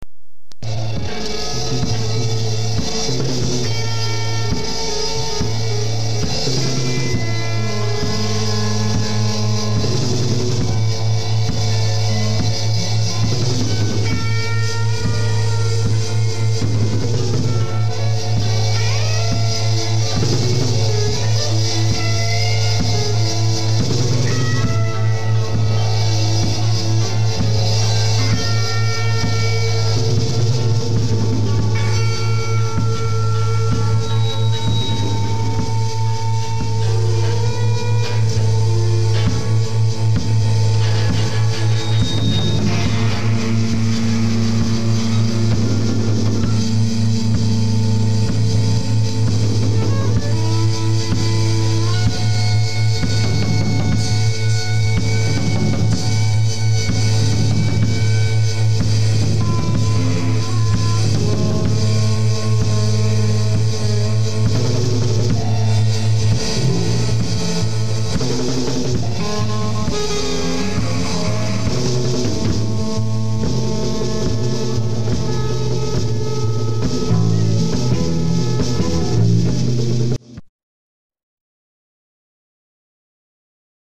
keyboards